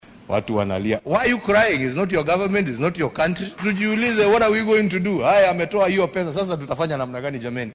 Hasa ahaate, Uhuru Kenyatta oo maanta hadal ka jeedinayay shirka sanadkan ee caafimaadka ee gobolka Bariga Afrika oo lagu qabanaya ismaamulka Mombasa ayaa sida uu hadalka u dhigay xusay in aan loo baahnayn in la calaacalo haddii Trump uu deeqda joojiyay.
Uhuru-Kenyatta.mp3